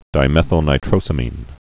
(dī-mĕthəl-nī-trōsə-mēn, -nītrō-sămĭn)